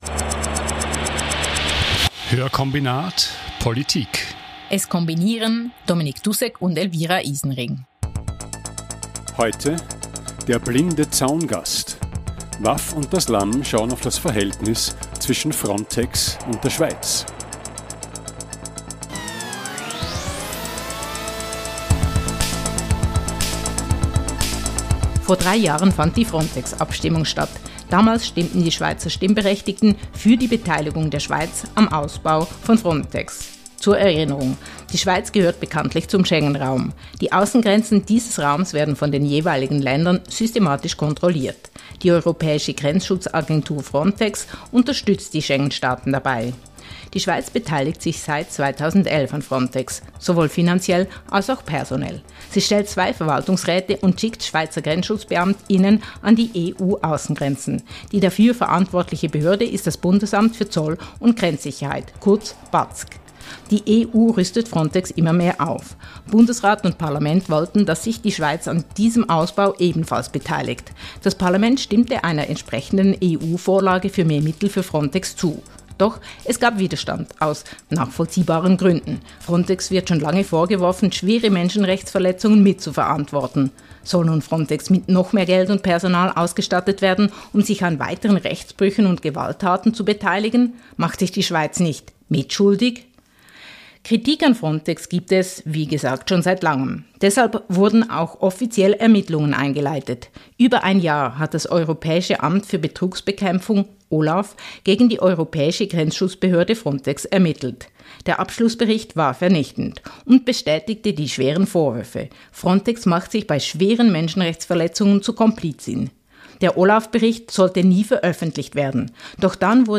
In jeder Folge bespricht das Audio-Duo Hörkombinat einen aktuellen Artikel mit einem/einer Journalist:in und ergänzt das Interview mit Hintergrundinformationen. Der Schwerpunkt liegt auf sozial- und wirtschaftspolitischen Themen.